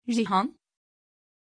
Prononciation de Jehan
pronunciation-jehan-tr.mp3